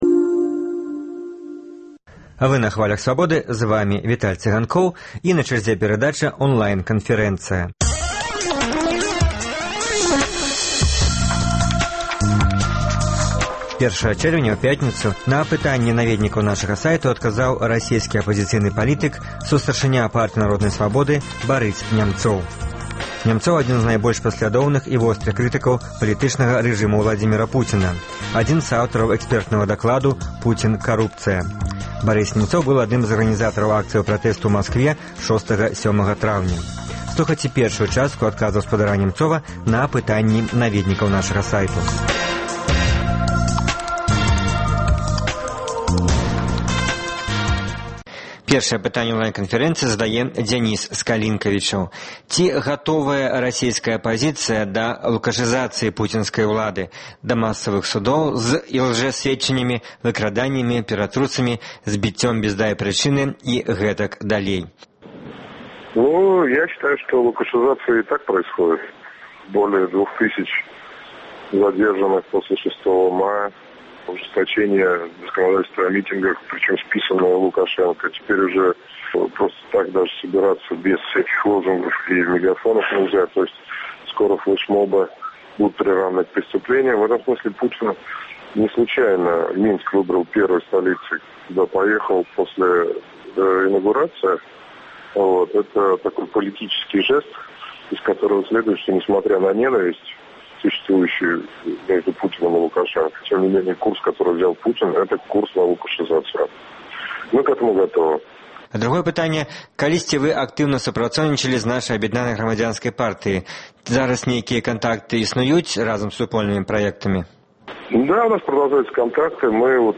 Радыёварыянт онлайн-канфэрэнцыі з расейскім апазыцыйным палітыкам, сустаршынём Партыі народнай свабоды Барысам Нямцовым.